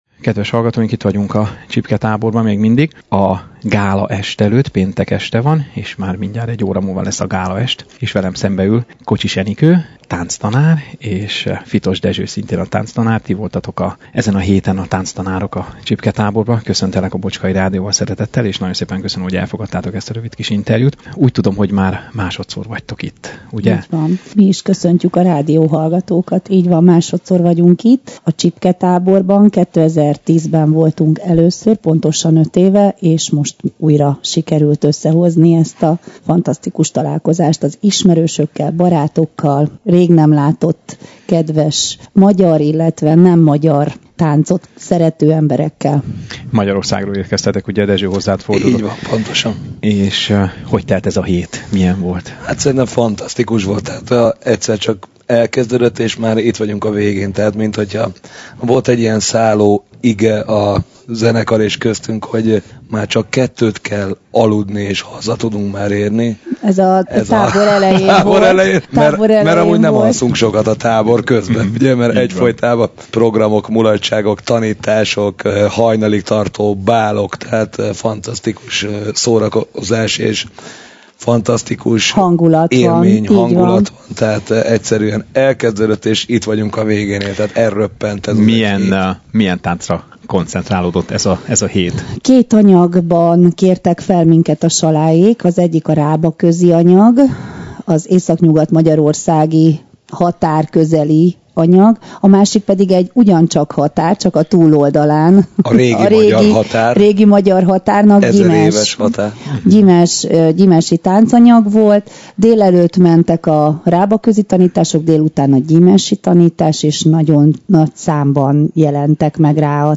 Őket is mikrofonvégre kaptuk egy utolsó ismétlő-gyakorlás és a gála előadás közt.